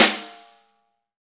Index of /90_sSampleCDs/Club-50 - Foundations Roland/KIT_xExt.Snare 4/KIT_xExt.Snr 4dS